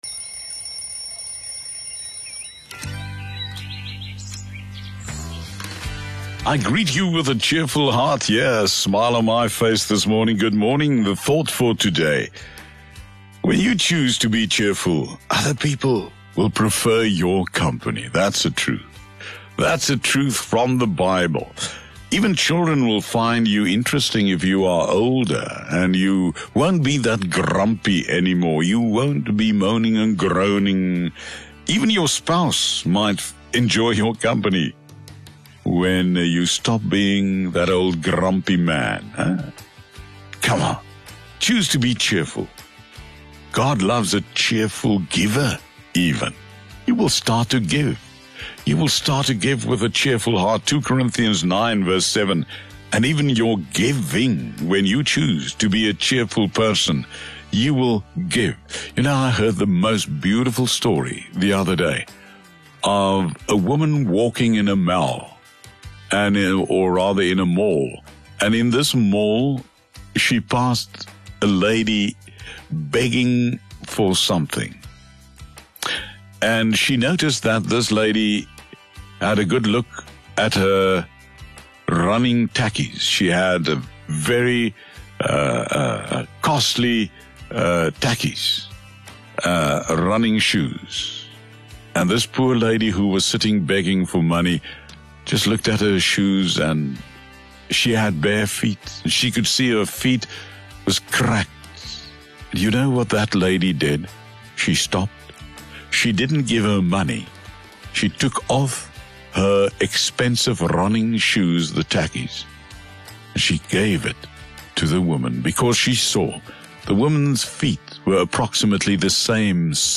Thought for the Day as heard on OFM on, 07 October 2021.